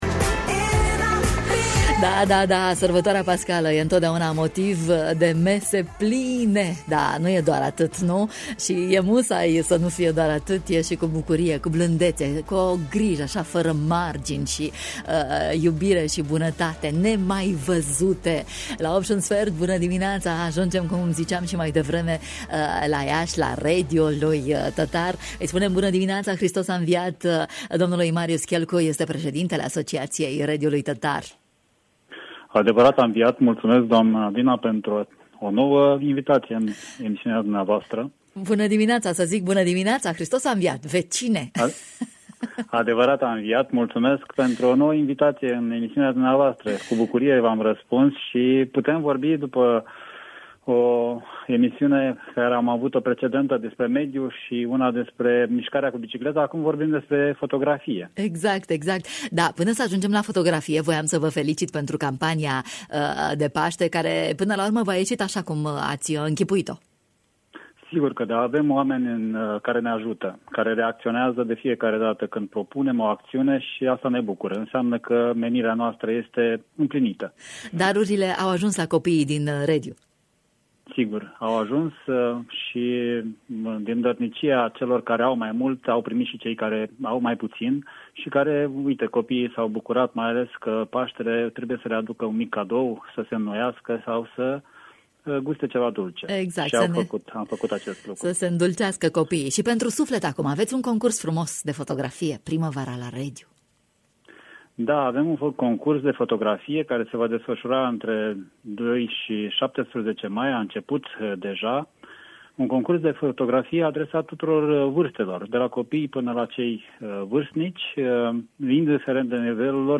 ne-a oferit mai multe detalii despre concurs în matinal: